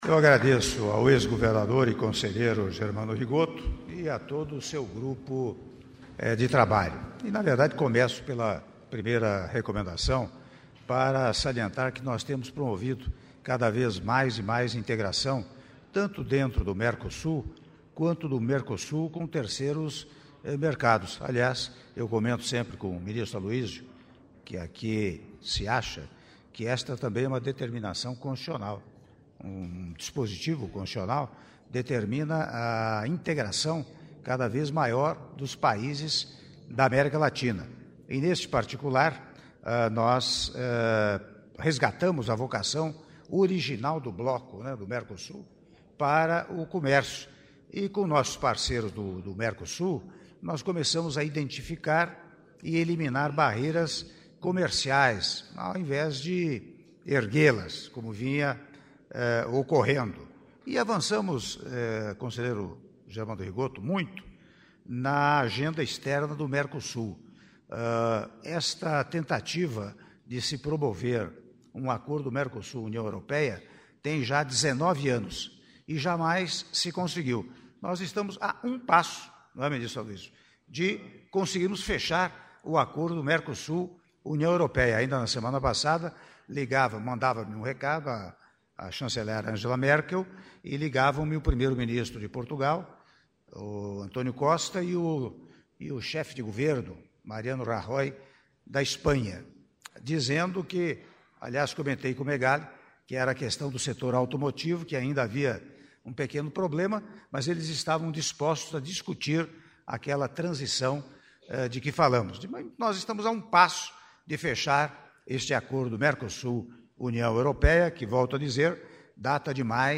Áudio do discurso do Presidente da República, Michel Temer, no encerramento da 47ª Reunião do Conselho de Desenvolvimento Econômico e Social - CDES - (05min13s) - Brasília/DF